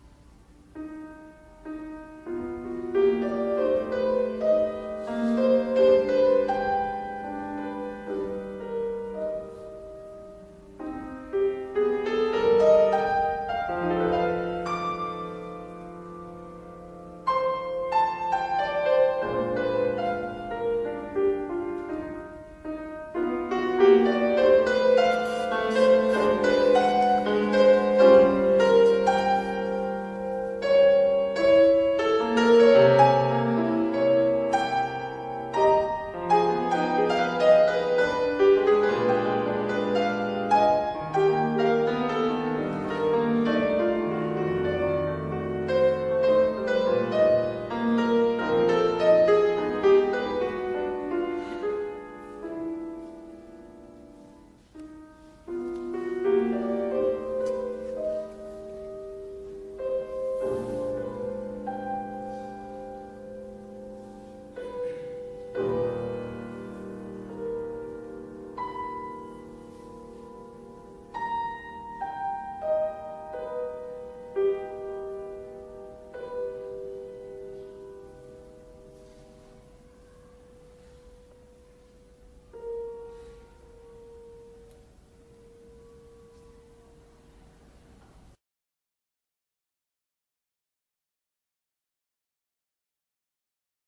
No parts available for this pieces as it is for solo piano.
Piano  (View more Intermediate Piano Music)
Classical (View more Classical Piano Music)